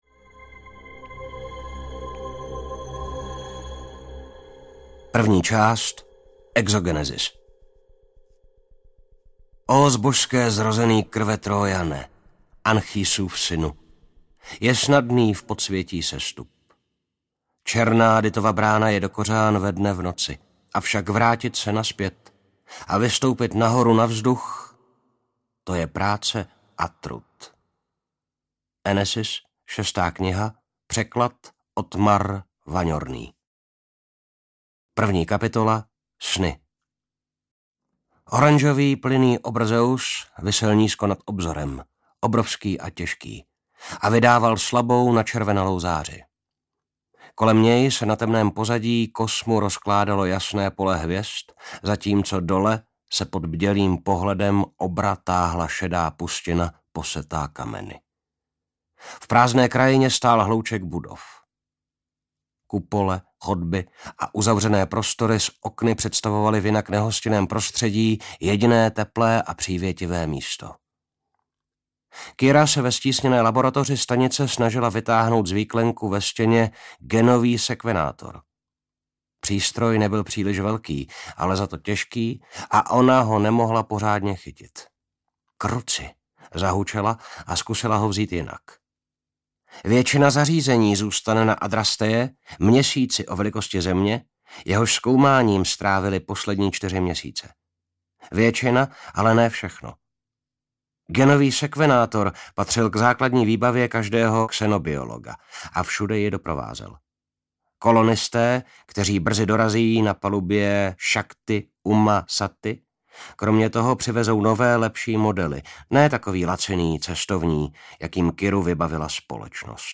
Spát v moři hvězd - Kniha I. audiokniha
Ukázka z knihy